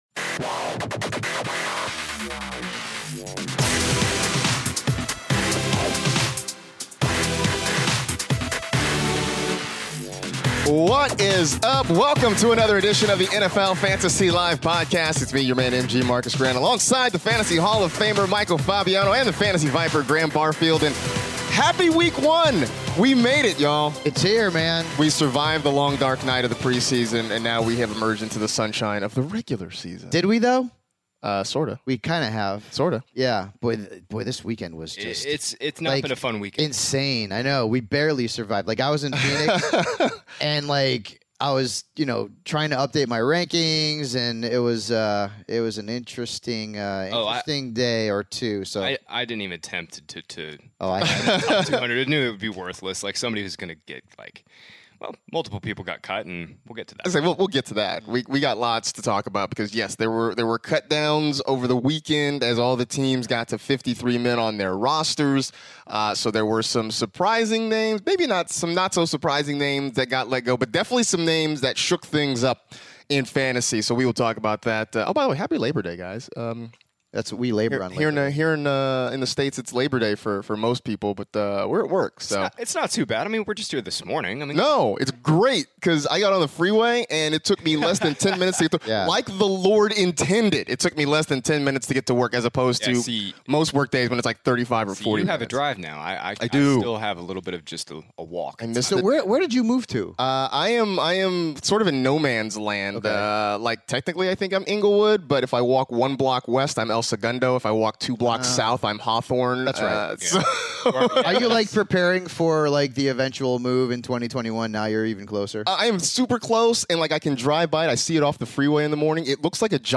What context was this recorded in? are in studio to record a brand new Fantasy Live Podcast for the start of Week 1!